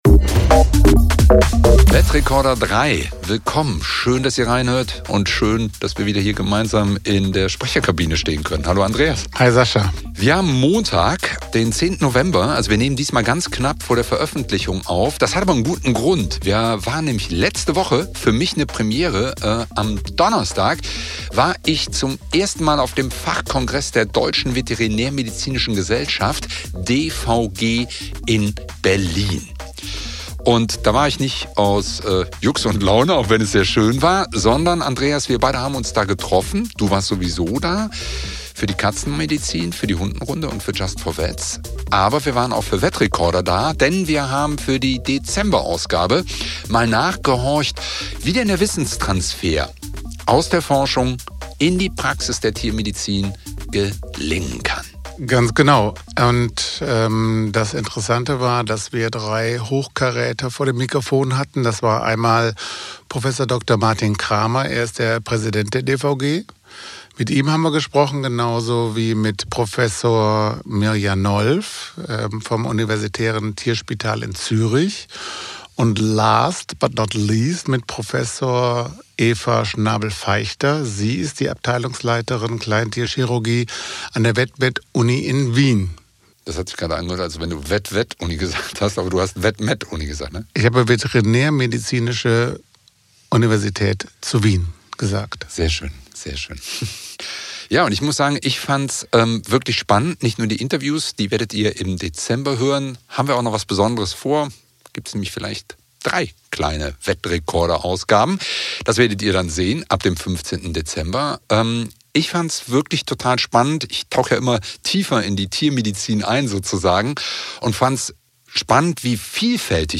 Im Vetzentrum Köln berichten Tierärzt:innen und TFAs von Stress, Dauerbelastung und strukturellen Herausforderungen – aber auch von wirksamen Schutzfaktoren und Teamstrategien. Ergänzt wird die Folge durch Stimmen der internationalen Organisation Not One More Vet (NOMV) sowie der deutschen Initiativen VETHiLFE e.V. und Vetivolution, die konkrete Unterstützung, Peer-Hilfe und Community-Arbeit leisten.